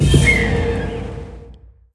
Media:RA_Bo_Evo.wav UI音效 RA 在角色详情页面点击初级、经典和高手形态选项卡触发的音效